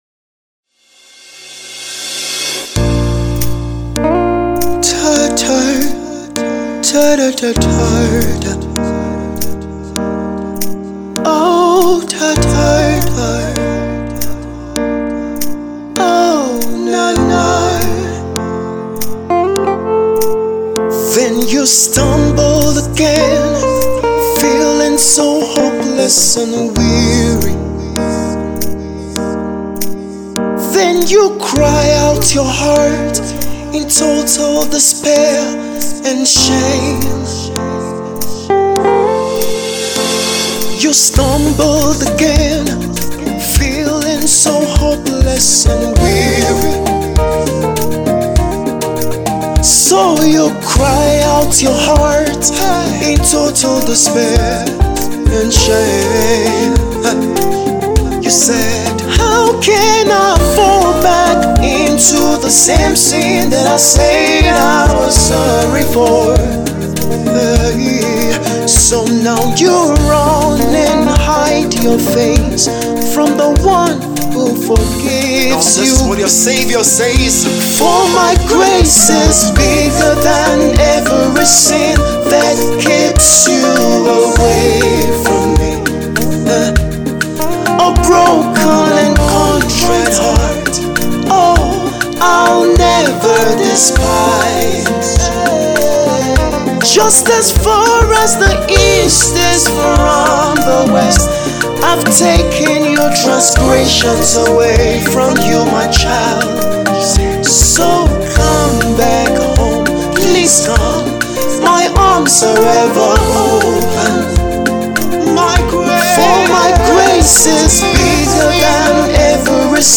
gospel piece